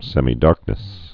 (sĕmē-därknĭs, sĕmī-)